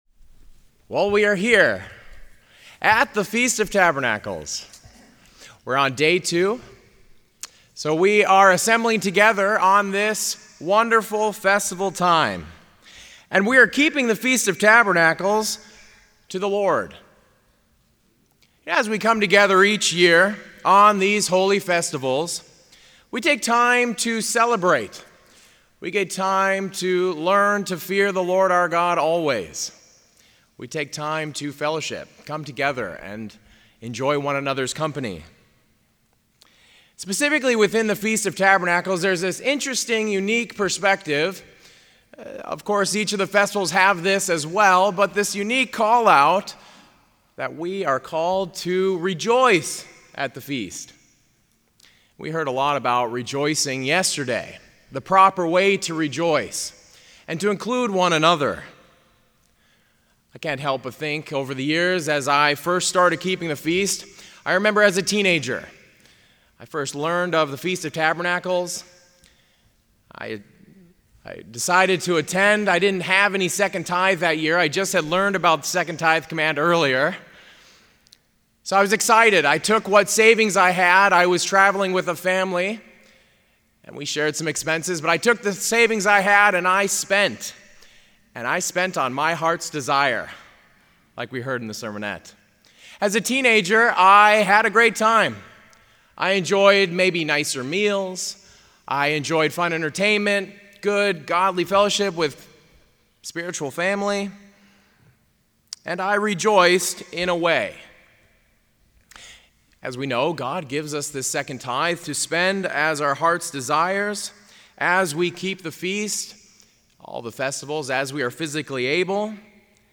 This sermon was given at the Jekyll Island, Georgia 2023 Feast site.